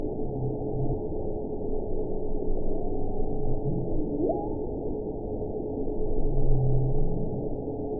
event 919034 date 12/23/23 time 17:31:26 GMT (4 months, 4 weeks ago) score 5.63 location TSS-AB07 detected by nrw target species NRW annotations +NRW Spectrogram: Frequency (kHz) vs. Time (s) audio not available .wav